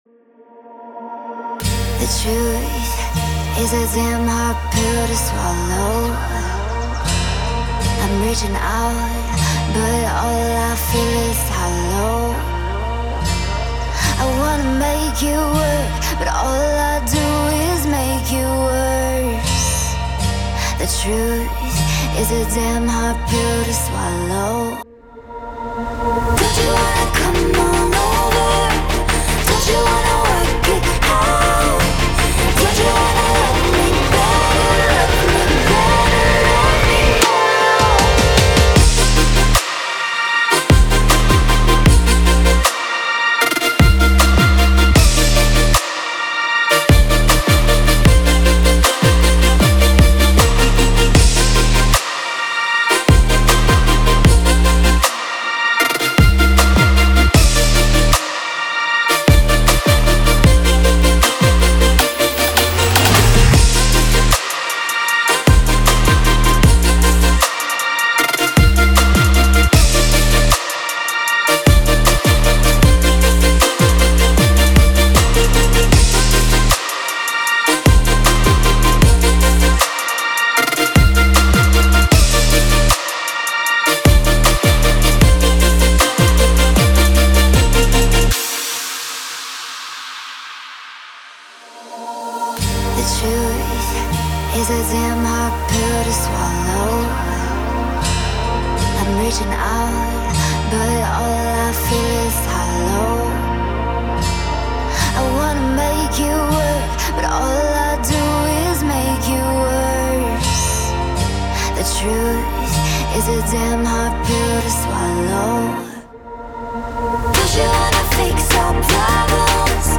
ищу людей, с кем можно вместе поработать в жанрах Future Bass и Melodic Dubstep, пишите, пообщаемся) ранние работы прикрепляю, для ознакомления